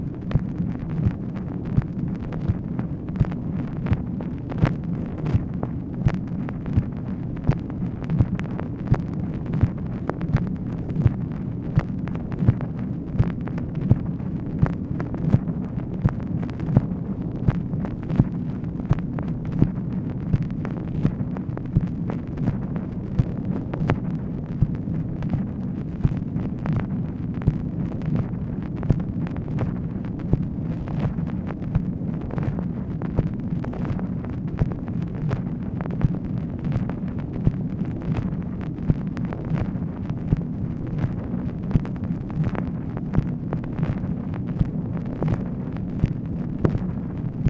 MACHINE NOISE, February 26, 1964
Secret White House Tapes | Lyndon B. Johnson Presidency